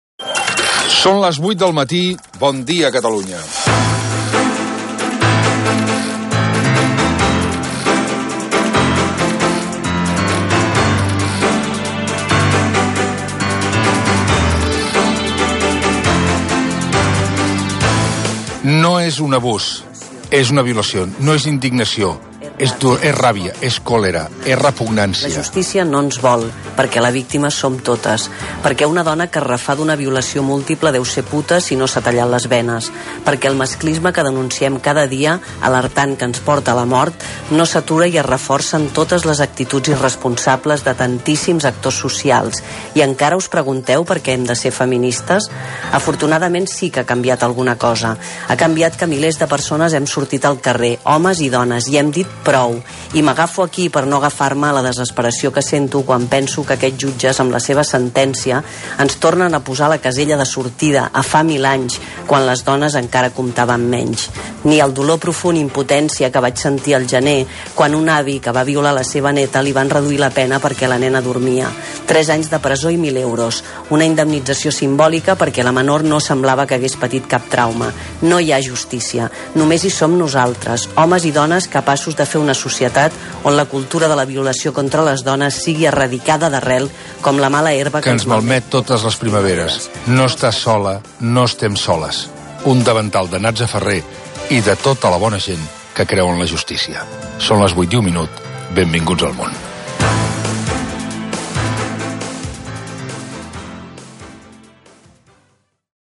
Hora. "El davantal", editorial crític amb la sentència de l'Audiència Provincial de Navarra a cinc membres de 'La Manada' que estaven acusats de la violació múltiple d'una jove madrilenya a l'inici dels Sanfermines del 2016, a Pamplona,